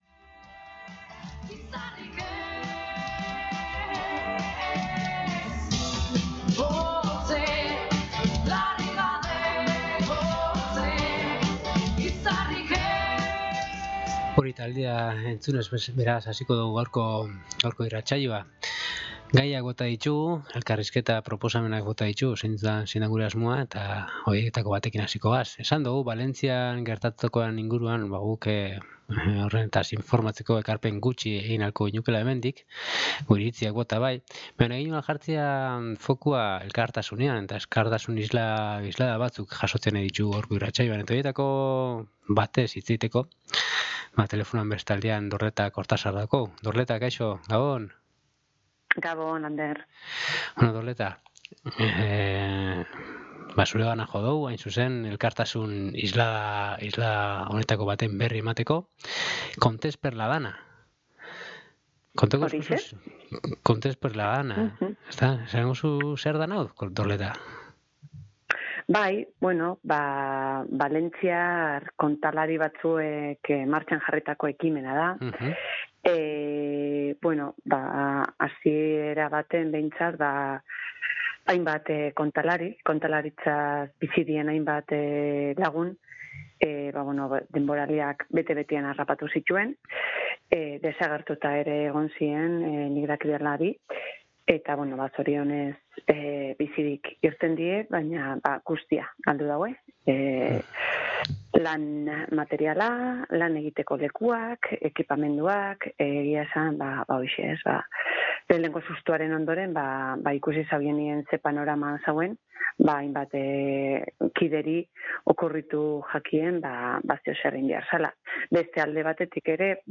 Berarekin hitz egin dugu KOLAX irratsaioan ekimenaren berri izateko.